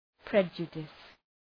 Προφορά
{‘predʒədıs}